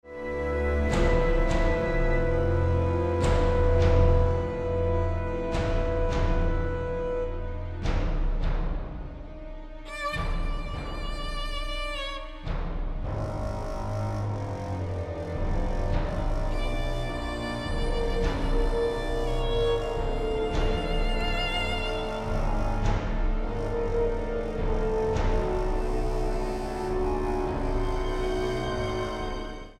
Genre: Classical / Halloween
for Solo Viola and Orchestra
Solo Viola and Solo Violin played and recorded by
Virtual Orchestra produced